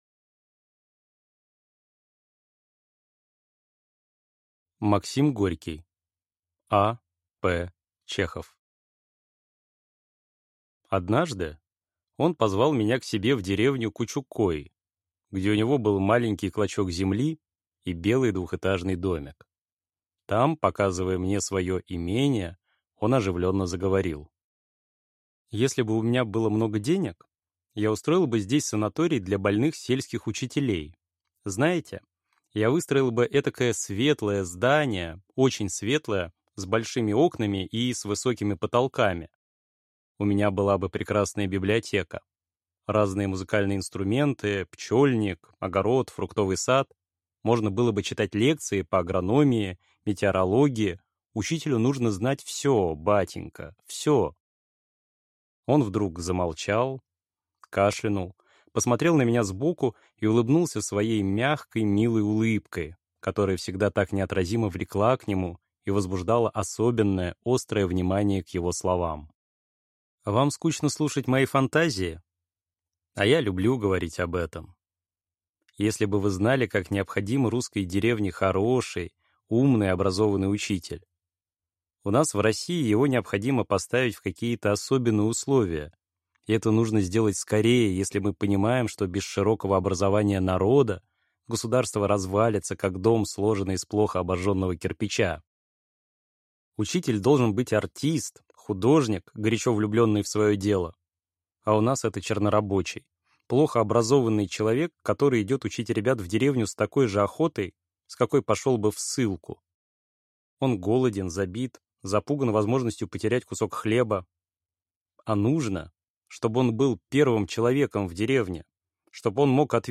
Aудиокнига А. П. Чехов